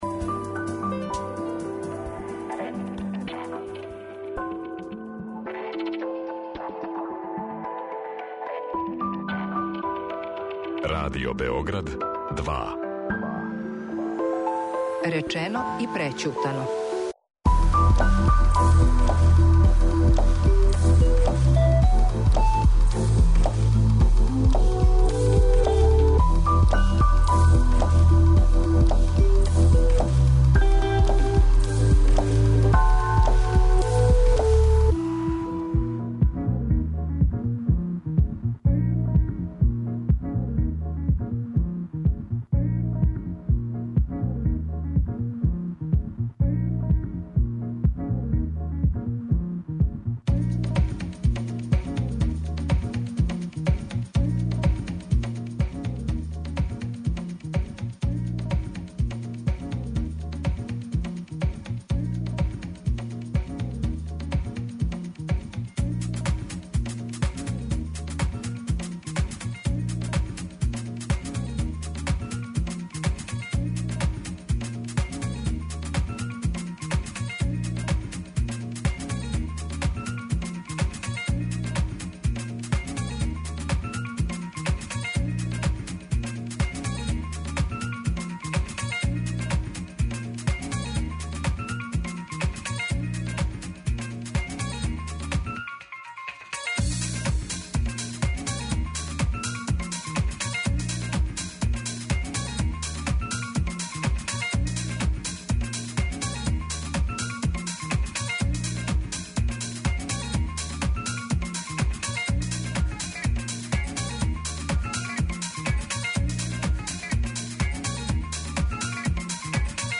Са нама уживо